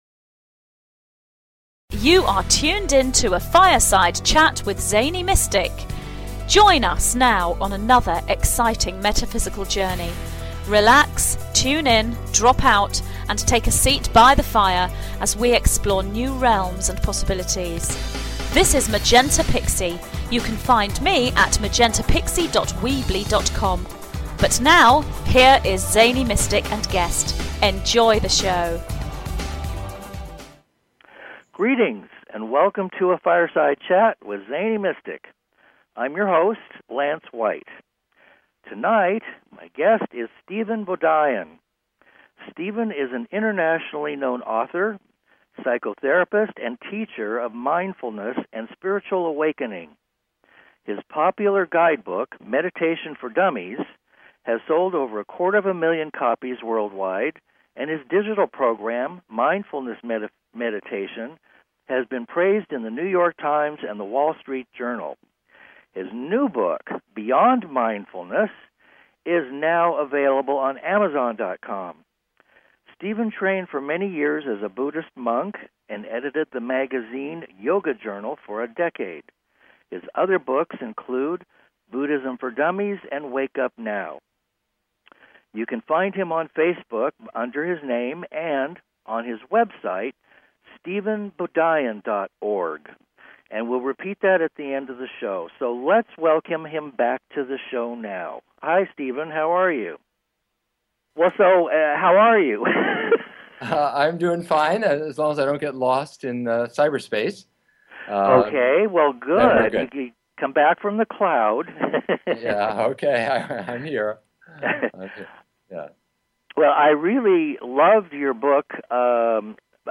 Talk Show Episode, Audio Podcast
This is a marvelous discussion with practical results.